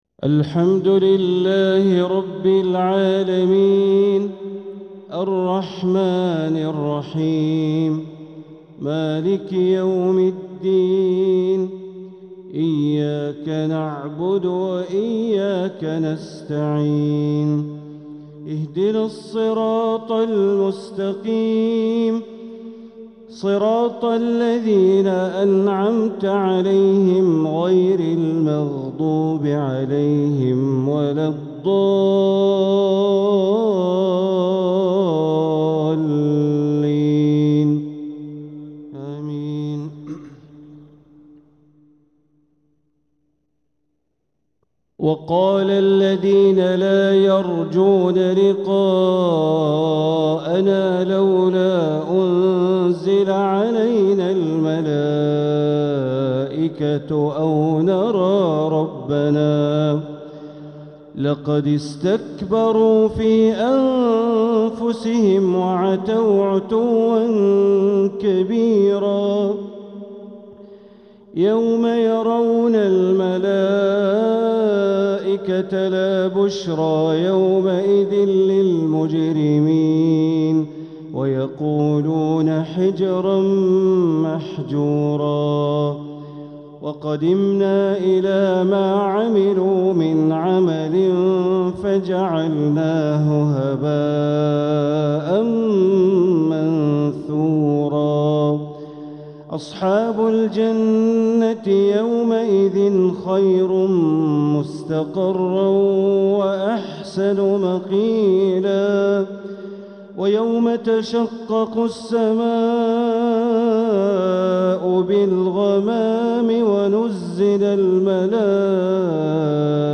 تلاوة من سورة الفرقان ٢١-٣٤ | عشاء السبت ٥ ربيع الآخر ١٤٤٧ > 1447هـ > الفروض - تلاوات بندر بليلة